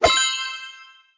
Angry Birds Space Crystal Collected Sound